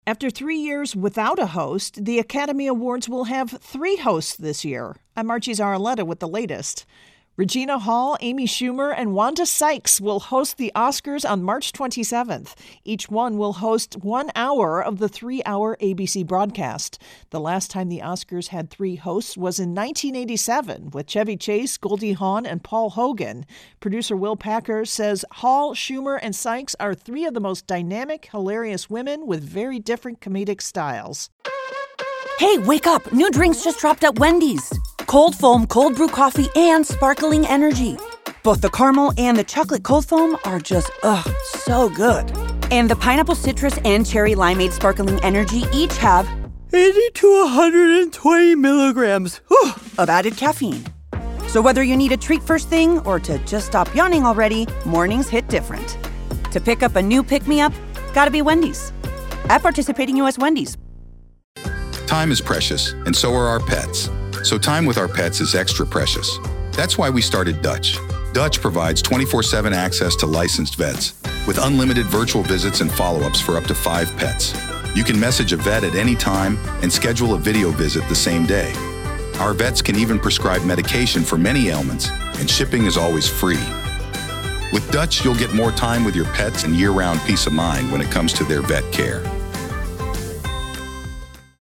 intro & voicer for Oscars Hosts